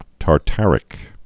(tär-tărĭk)